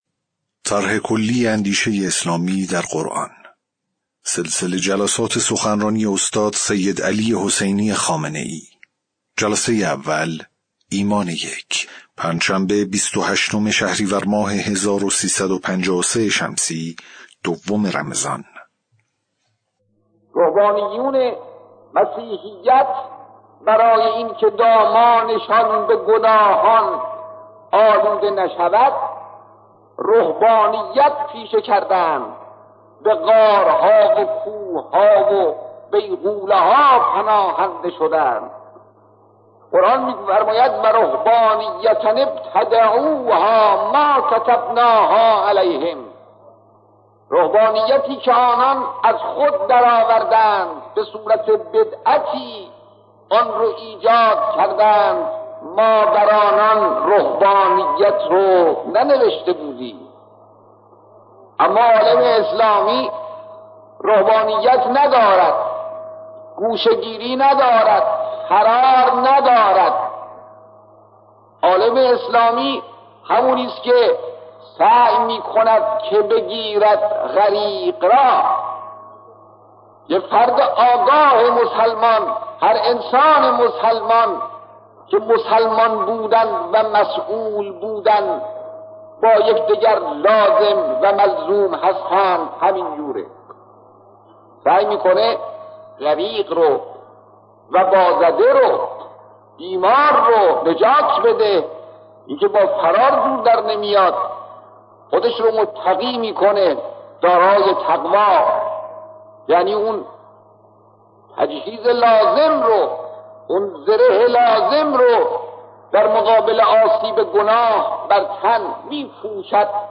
سخنرانی قدیمی